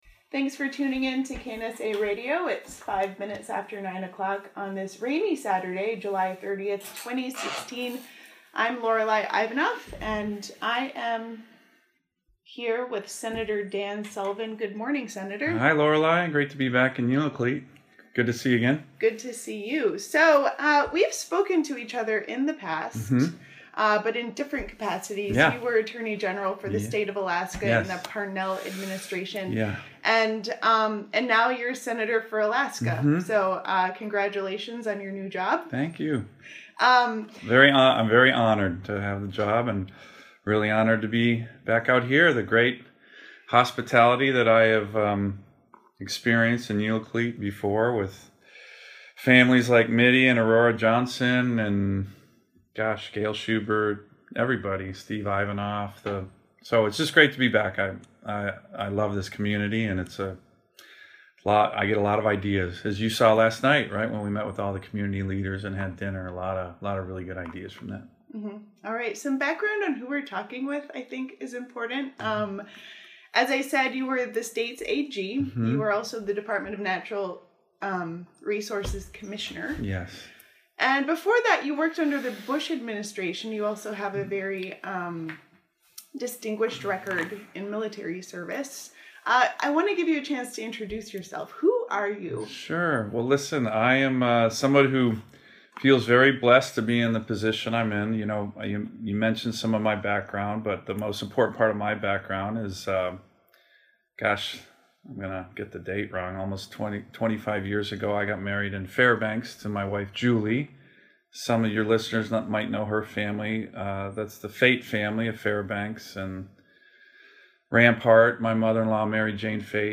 We talked about his address at the Republican National Convention and his support for Donald Trump as the President of the United States. We also talked about the Arctic and his view on how the government can work with indigenous people. We talked about a lot during this stop at the station.